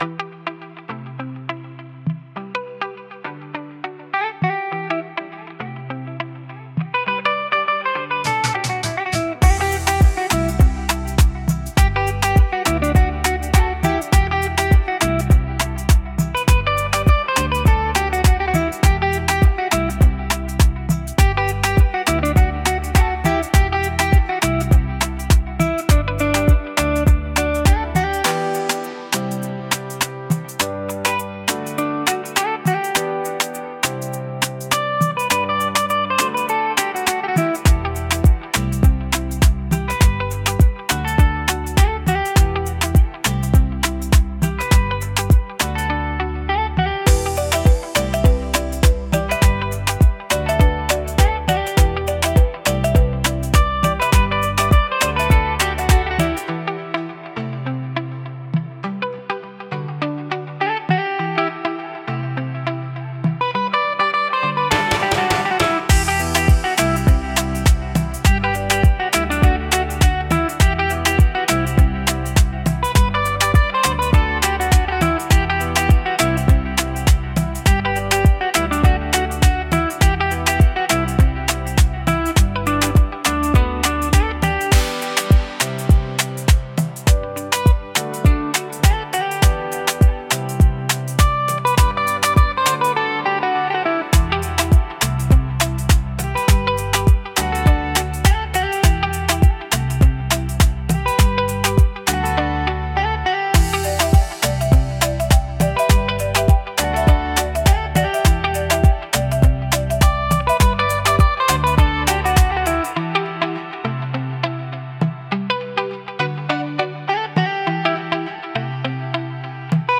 128 BPM
Trap